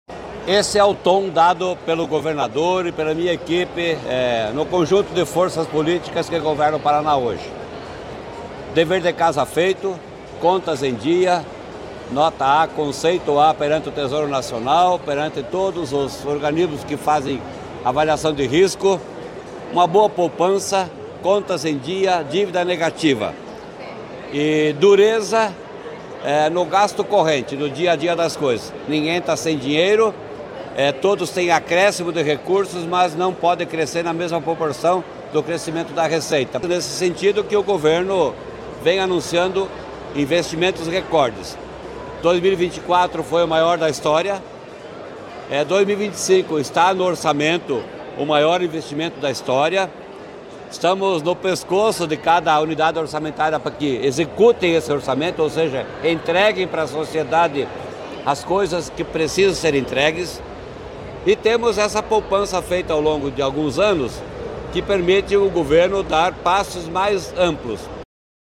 Sonora do secretário da Fazenda, Norberto Ortigara, sobre o anúncio de pacote de R$ 6 bilhões de investimentos nos municípios paranaenses